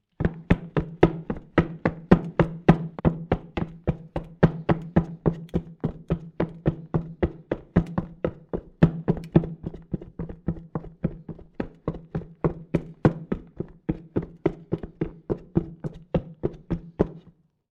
Footsteps
NormalWood_Boots_Running.wav